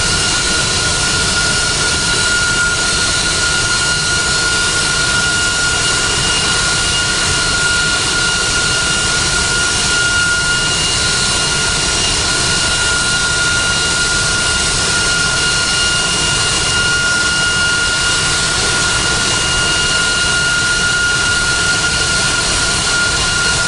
cfm-spoolClose.wav